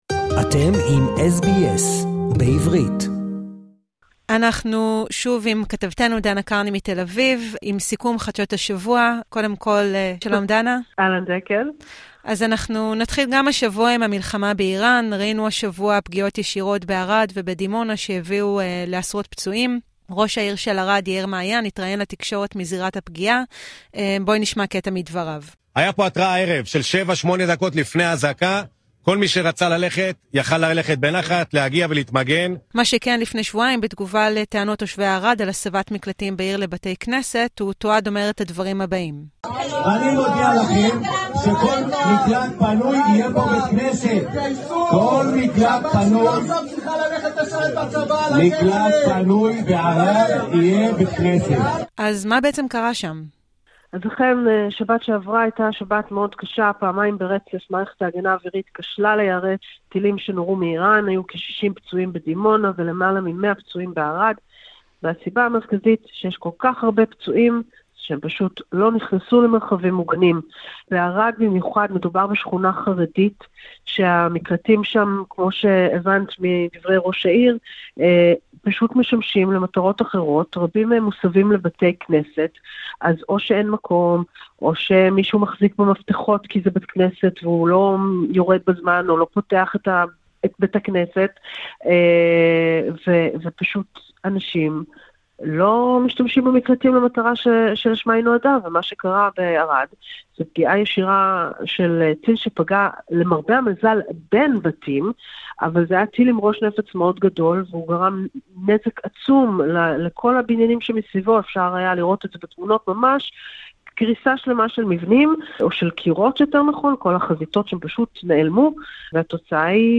השבוע שהיה - סיכום חדשות מישראל